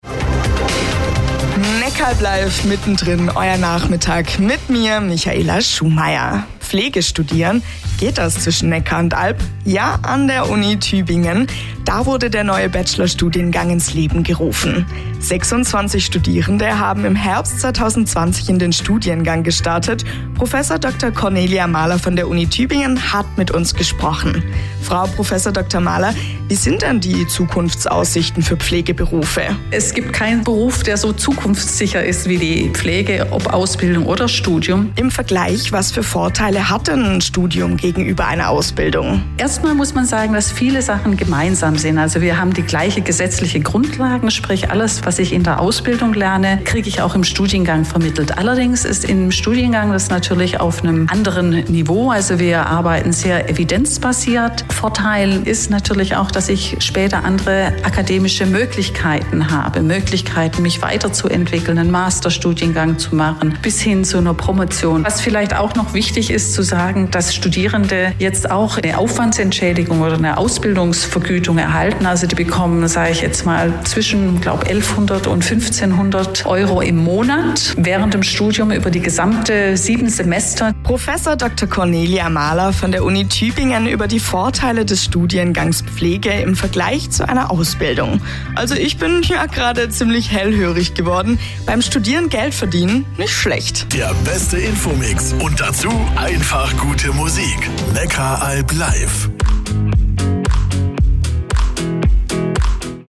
Interview mit Radioneckaralb zum Studiengang Pflege